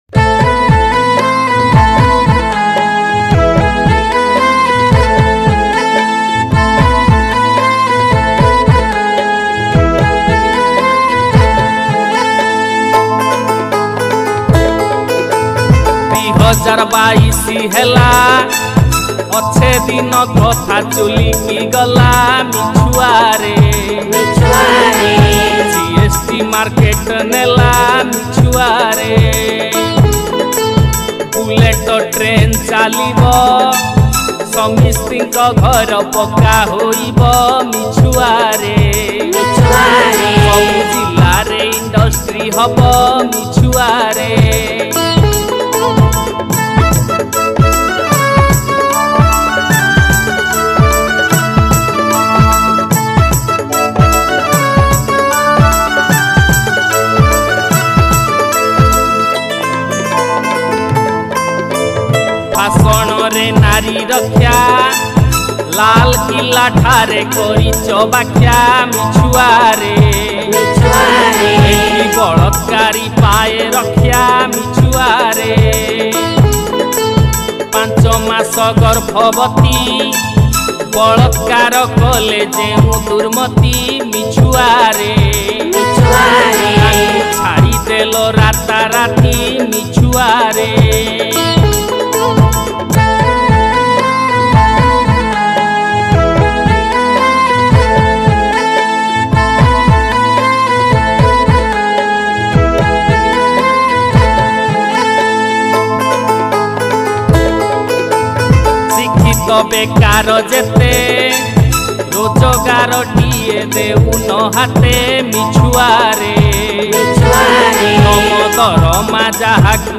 odia album song